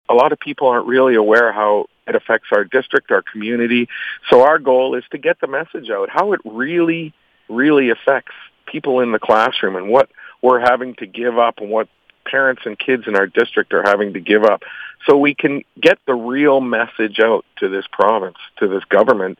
Board Chair Steve Rae says the district is working on a communications strategy to get the word out.